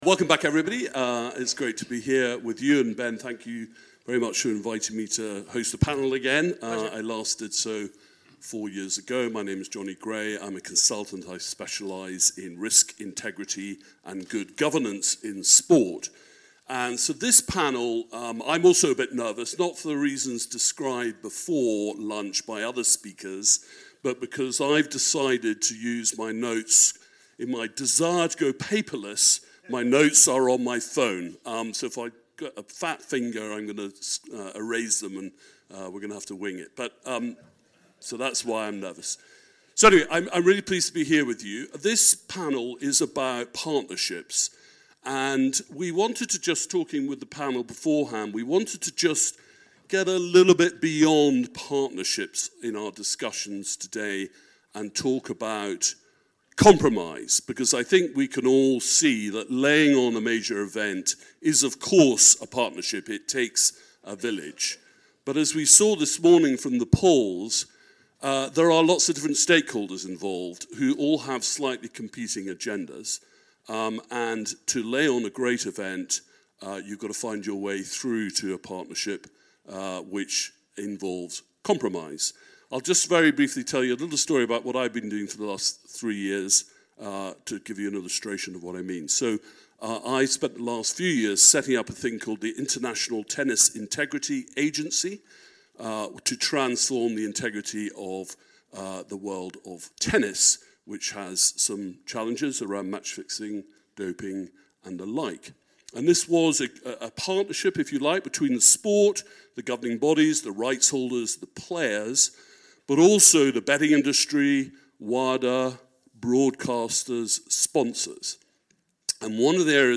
Host City 2022 Panel 4: Partnership paradigms of event hosting and delivery